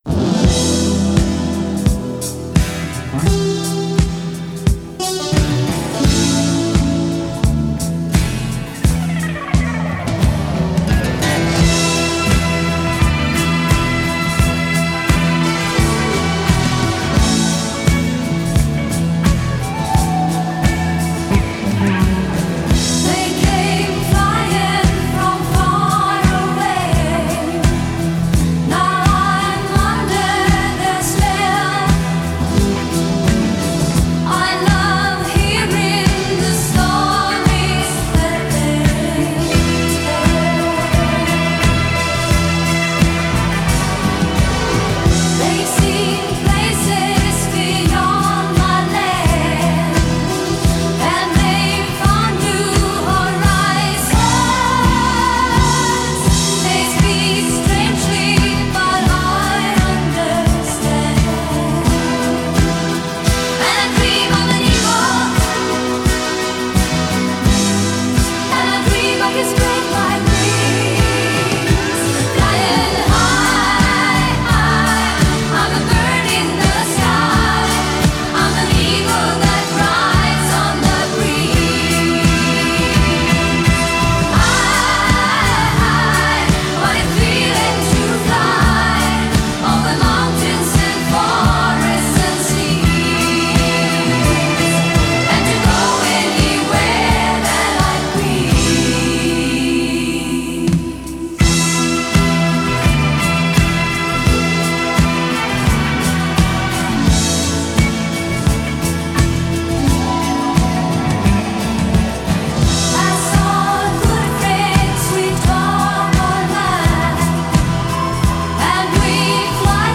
Pop, Disco, Europop, Art Rock, Pop Rock